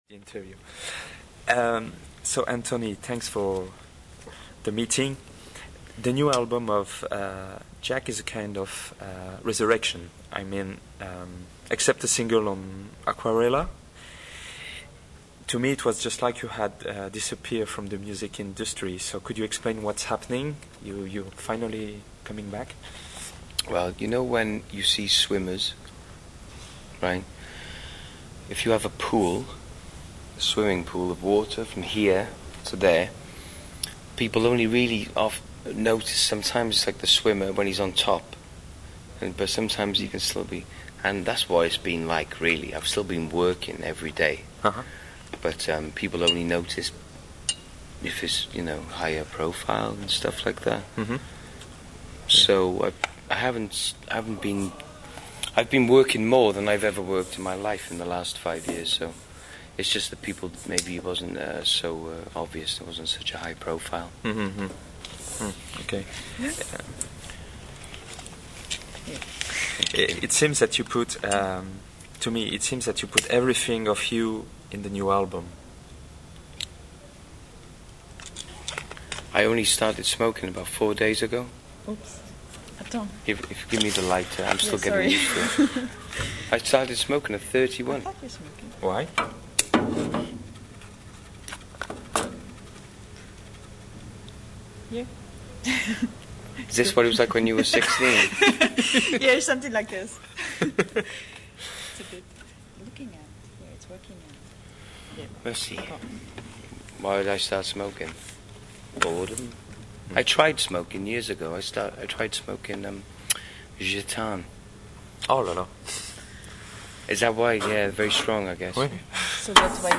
Rock and folk - interview-France-2002